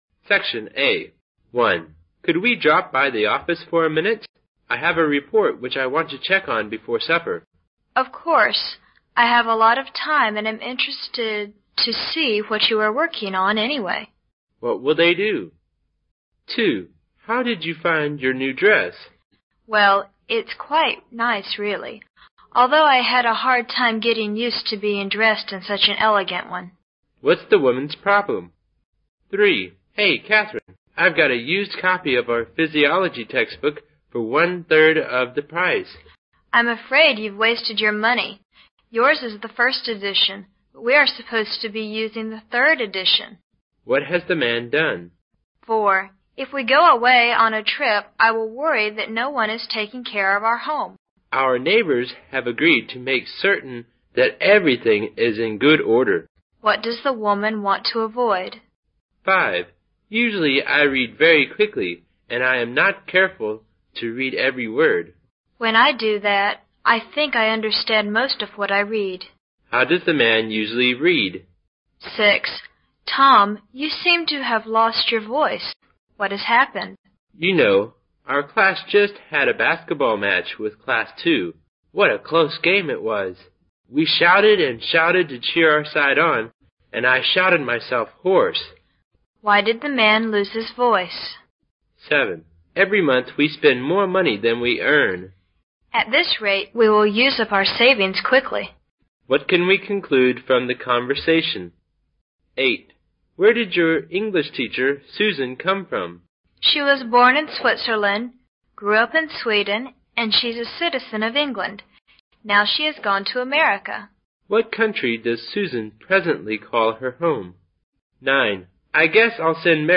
决胜六级听力必备 mode8